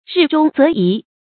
日中则移 rì zhōng zé yí
日中则移发音